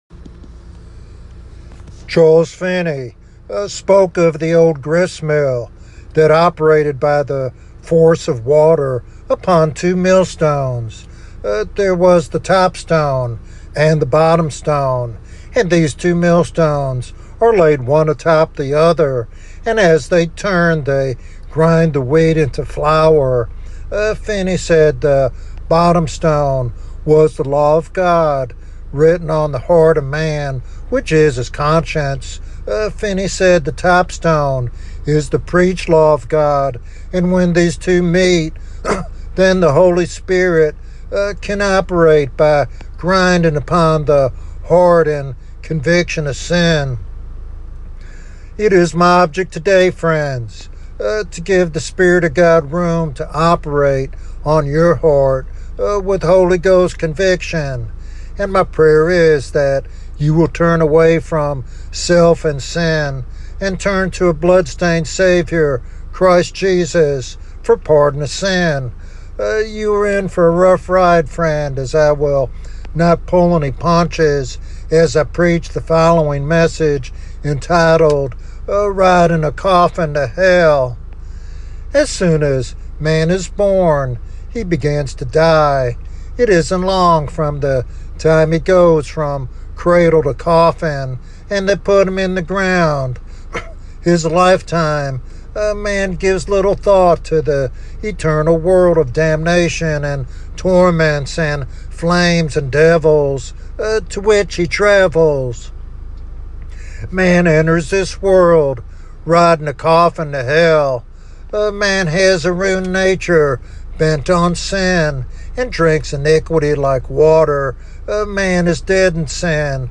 This sermon challenges complacency and invites all to turn to the Savior before it is too late.